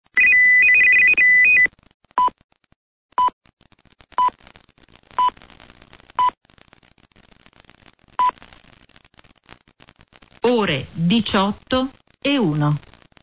Segnale_orario.mp3